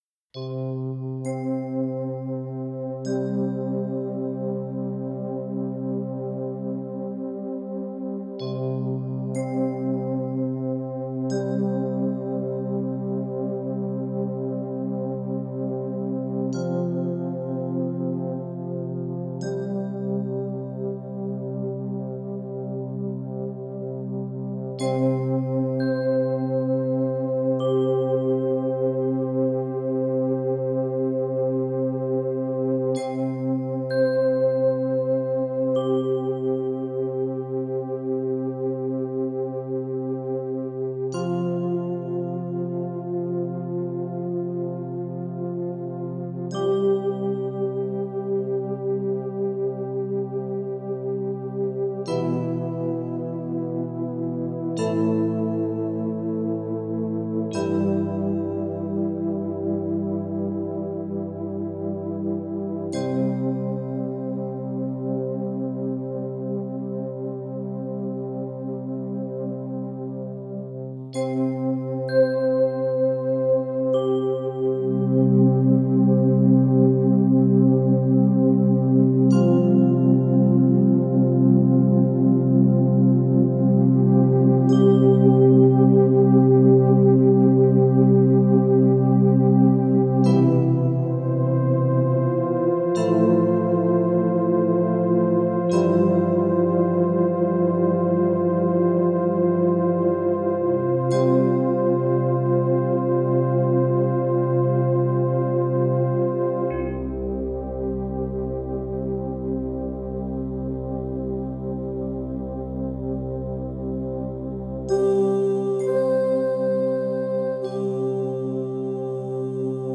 Since it only used three patches it was pretty simple.